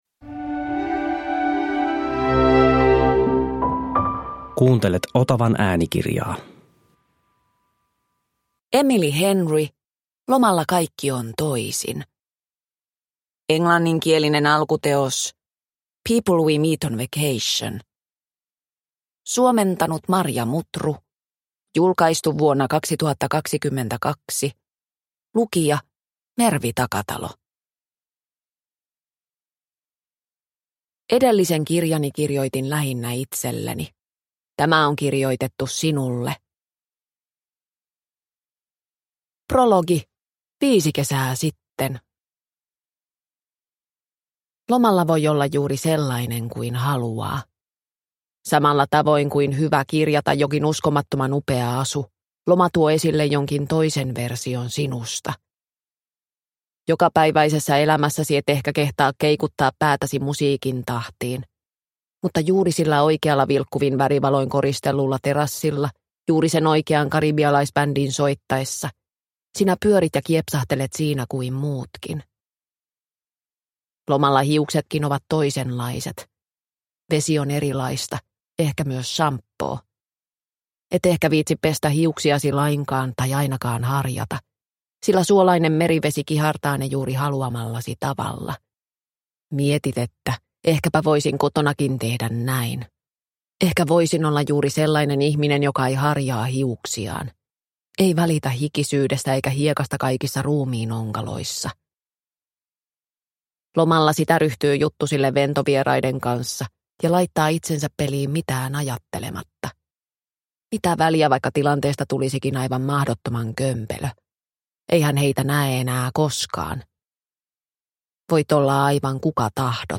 Lomalla kaikki on toisin – Ljudbok – Laddas ner